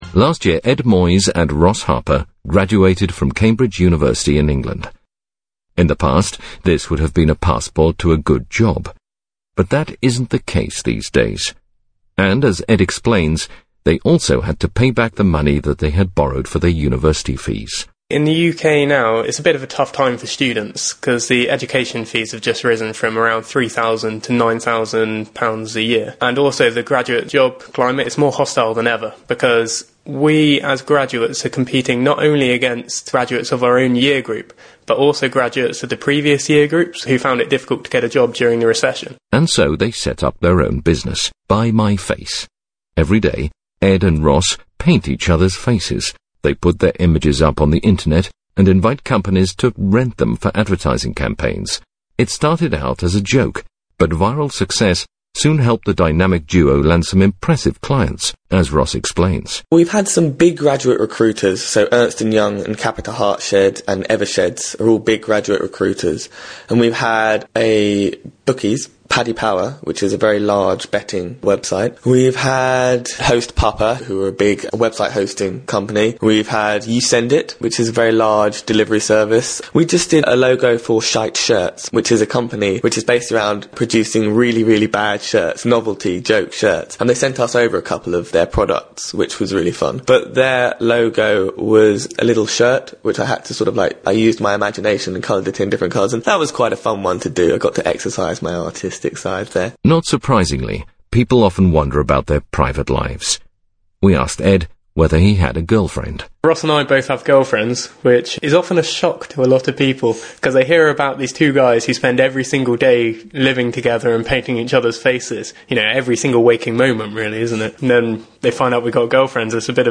Buy My Face (interview)